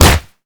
kick_heavy_impact_06.wav